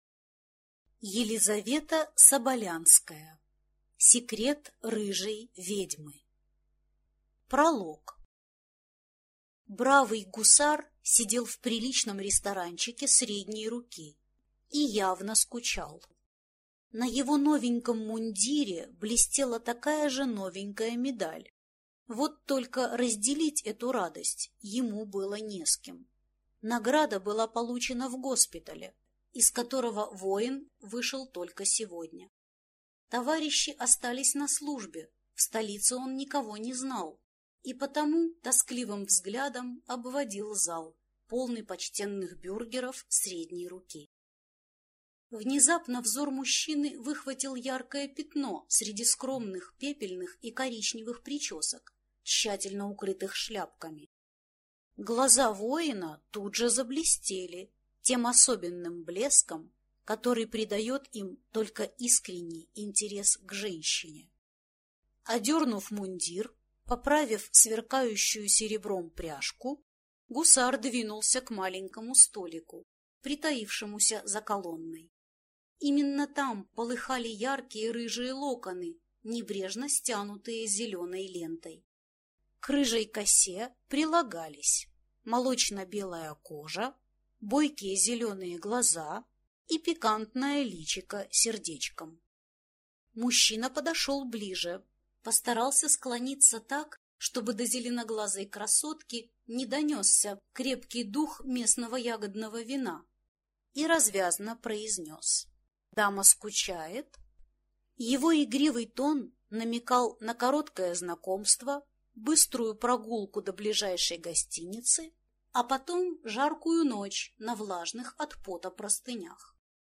Аудиокнига Секрет рыжей ведьмы | Библиотека аудиокниг